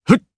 Kibera-Vox_Casting1_jp.wav